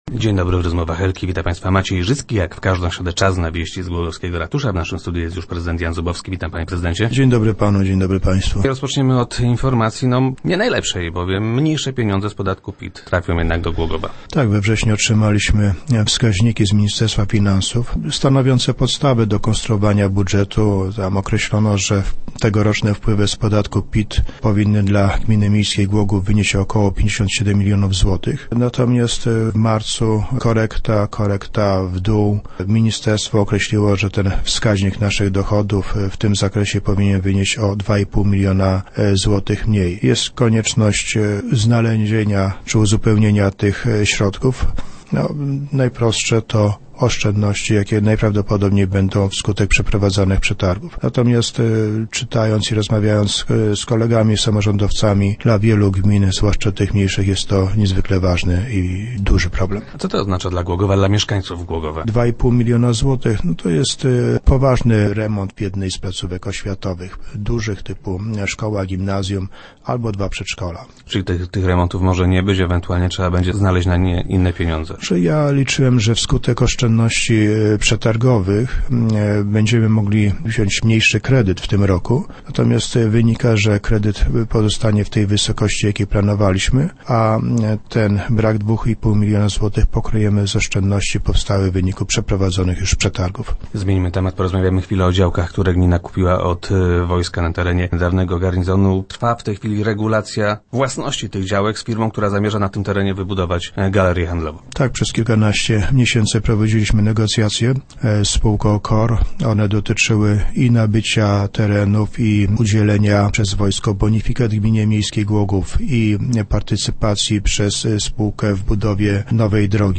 - Ministerstwo finansów skorygowało wskaźnik naszych dochodów - informuje prezydent Jan Zubowski, który był gościem Rozmów Elki.